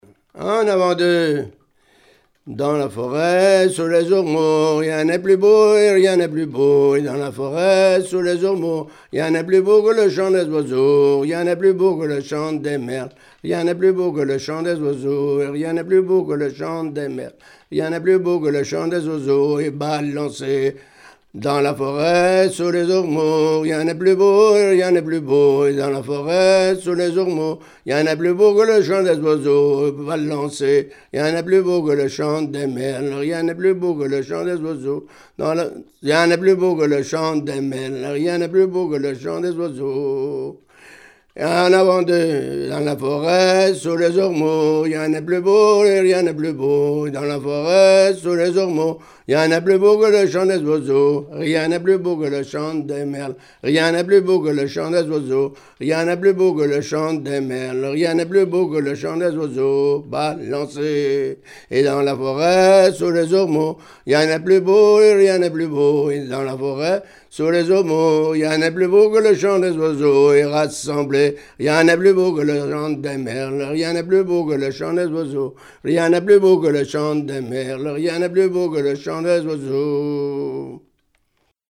Mémoires et Patrimoines vivants - RaddO est une base de données d'archives iconographiques et sonores.
Chants brefs - A danser
danse : branle : avant-deux
Pièce musicale inédite